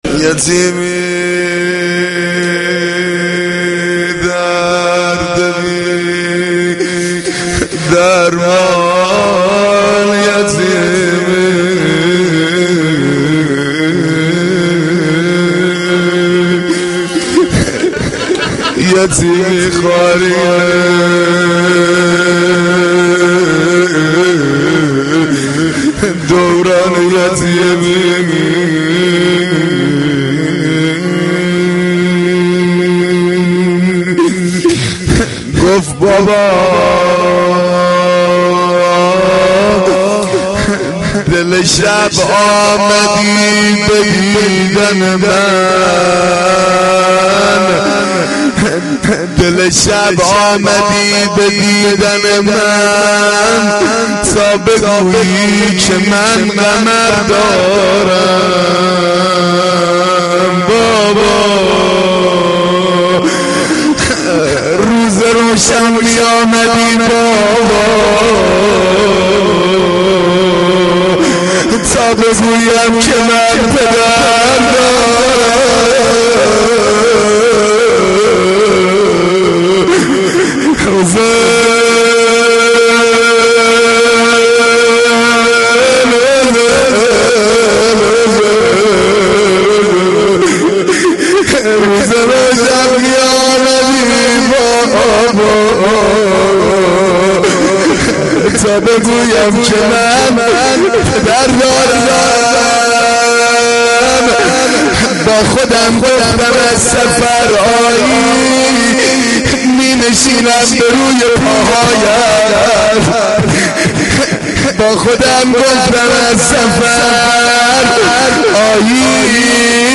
مداحی روضه شب سوم